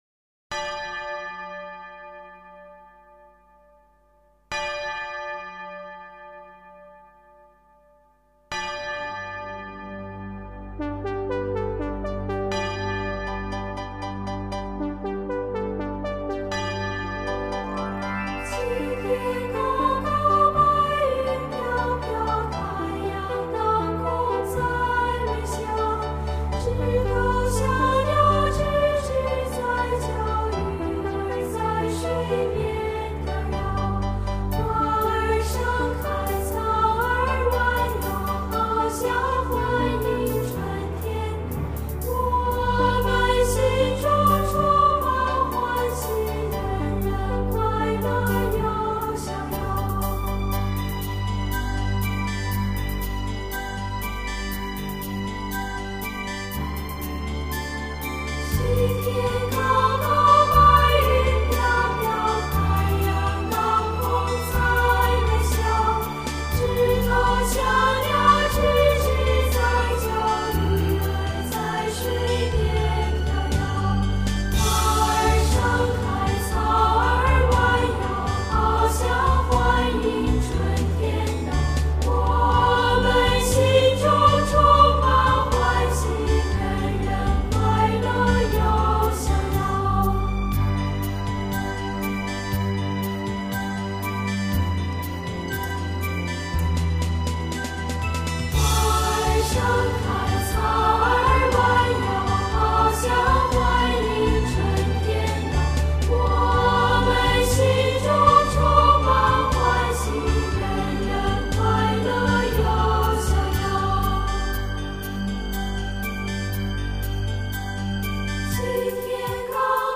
童声合唱是一种广受欢迎的形式，它包含错落的声部构成
与和谐悠扬的齐唱。